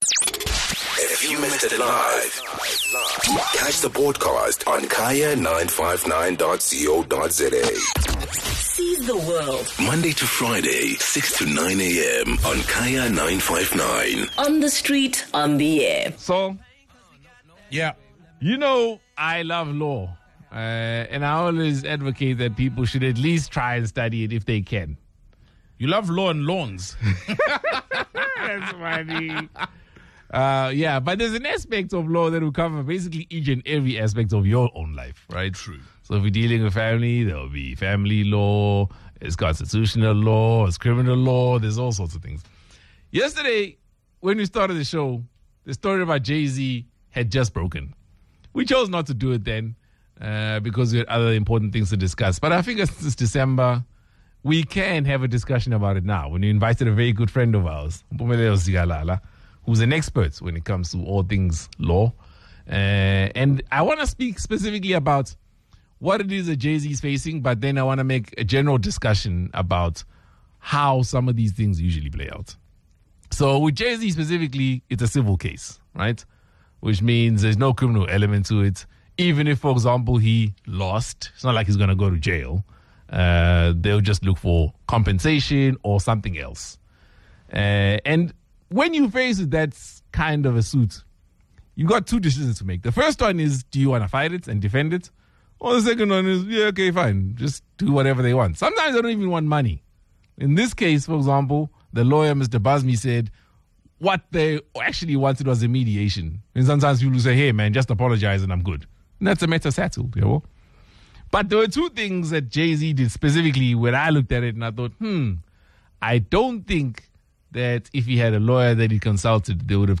Shortly after, Jay-Z responded with a fiery statement on X through his entity Roc Nation; to make it clear he was ready to fight back. Sizwe Dhlomo invited Legal Expert